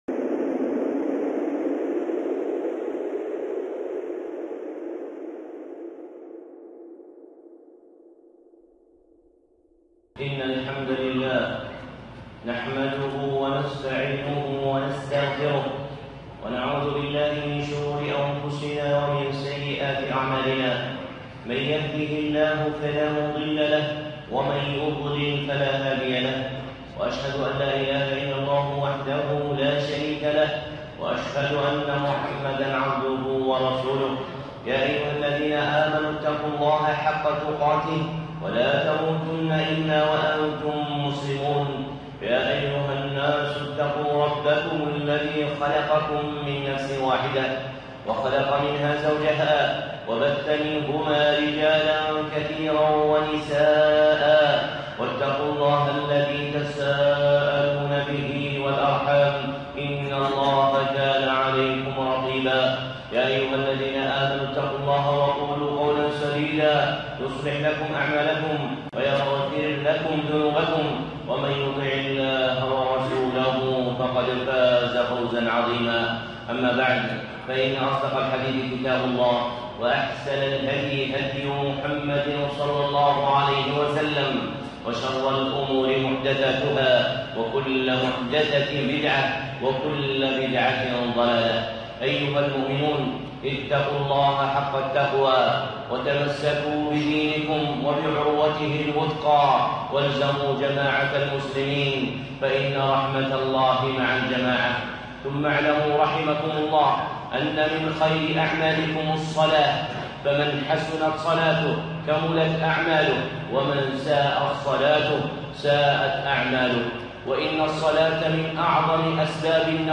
خطبة (من نورانية الصلاة